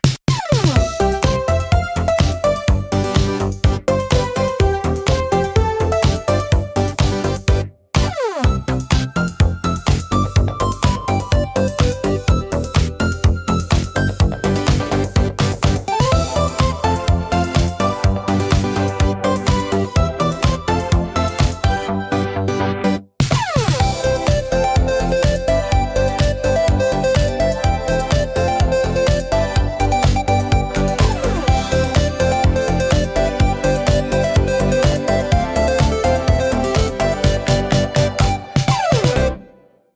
pop
当サイトの音素材は、Suno（有料プラン）または Sora（Sora 2）を利用して制作しています。